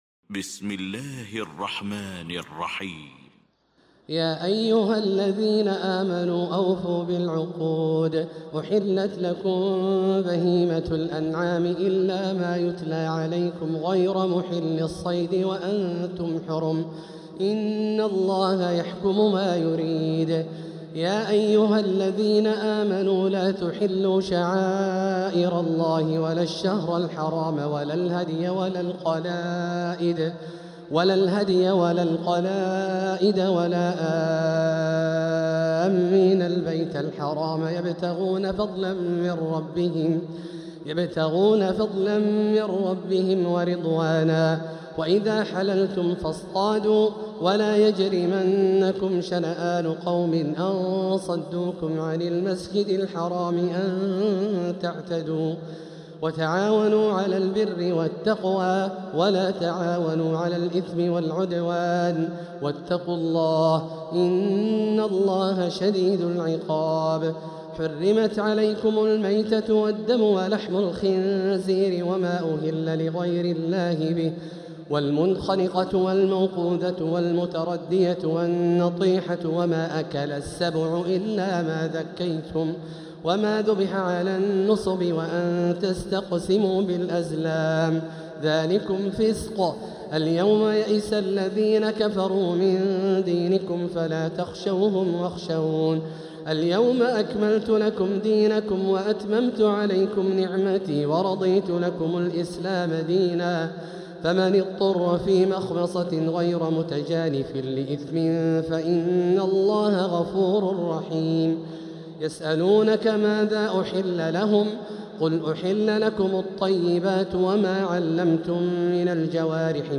سورة المائدة Surat Al-Ma'idah > مصحف تراويح الحرم المكي عام 1447هـ > المصحف - تلاوات الحرمين